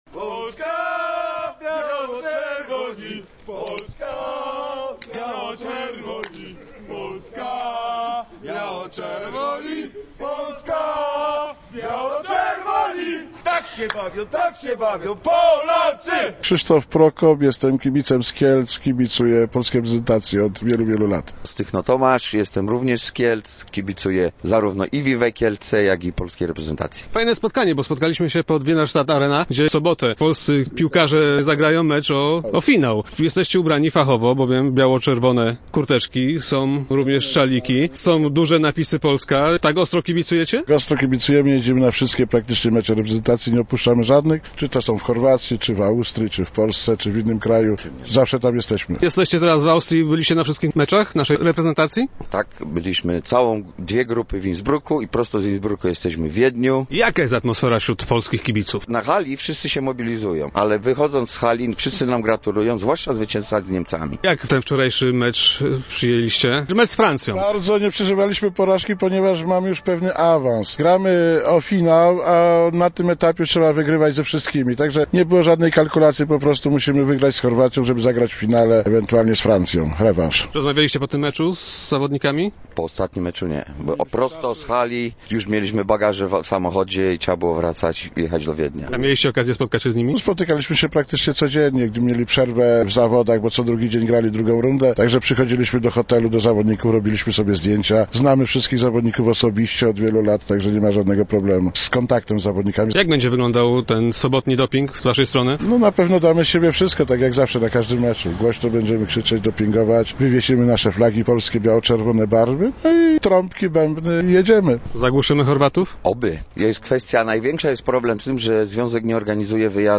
Przed halą spotkaliśmy kibiców z Kielc.
0130_wieden_kibice.mp3